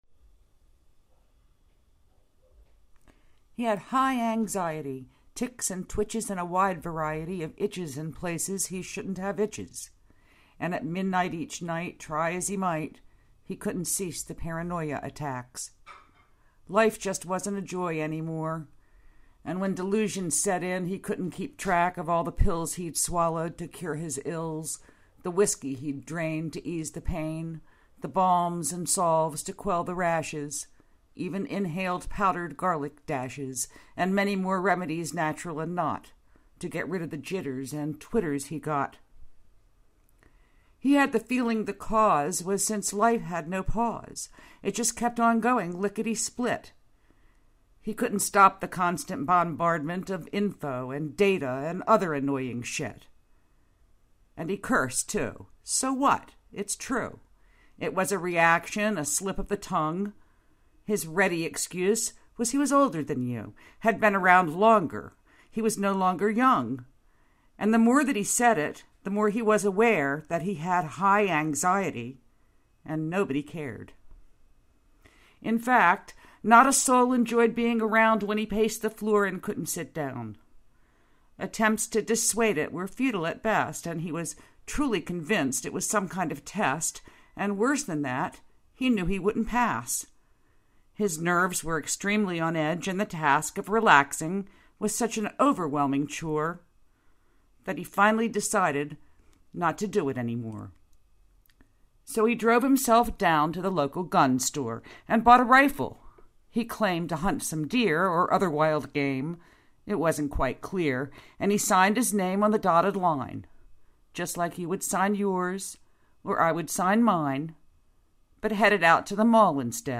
I should put some music to it.
The room you are recording in now is very bouncy.)
(excuses excuses, i know, but yanno what we always say? the materials at hand..... i was reading it off the screen... saves on ink and paper, too, 'cause i don't have a laptop to take into the closet...